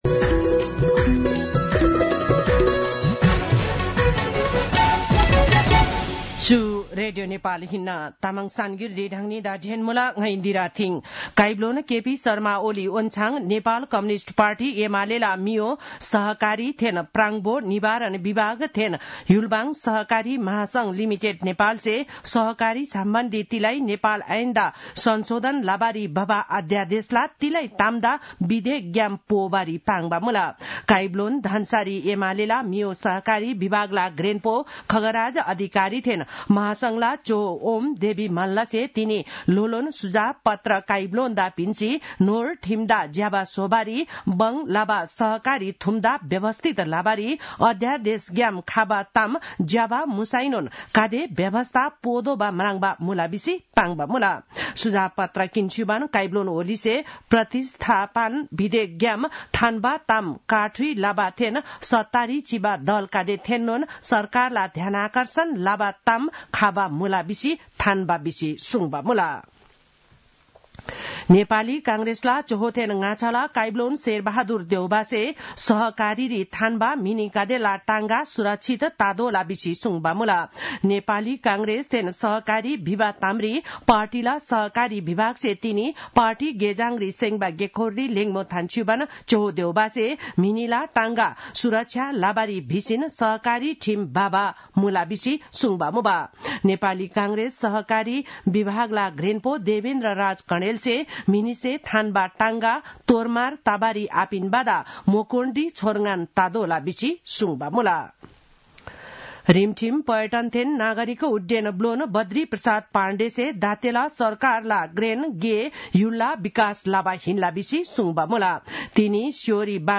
तामाङ भाषाको समाचार : २० माघ , २०८१